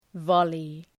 Προφορά
{‘vɒlı}